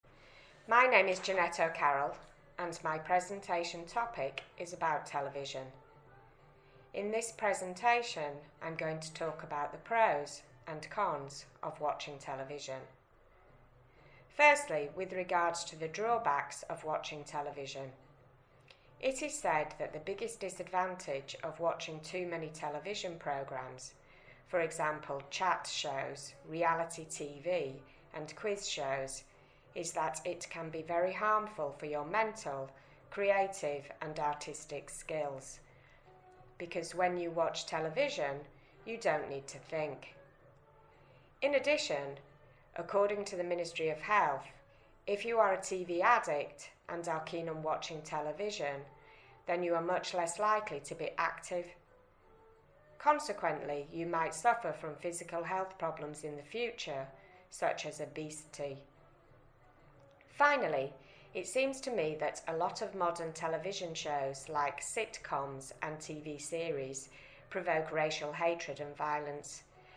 1: This model presentation responds to the following EOI B1 exam question: